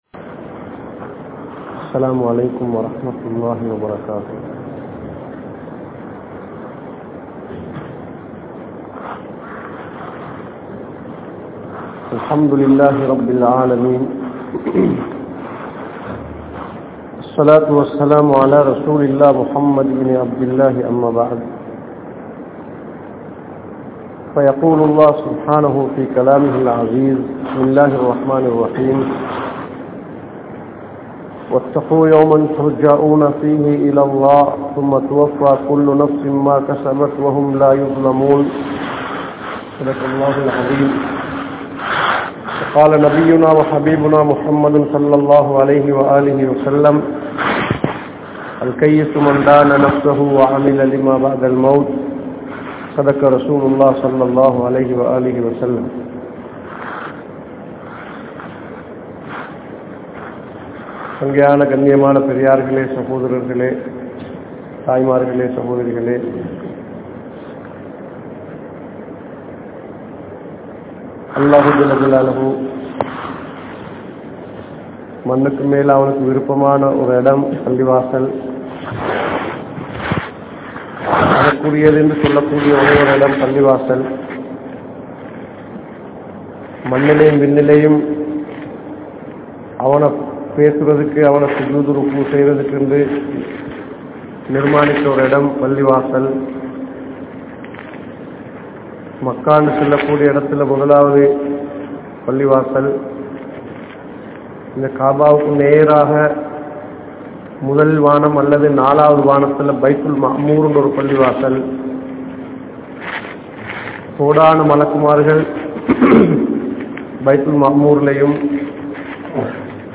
Paavaththil Perumai Adippavarhal (பாவத்தில் பெருமை அடிப்பவர்கள்) | Audio Bayans | All Ceylon Muslim Youth Community | Addalaichenai